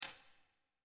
drop_gold.mp3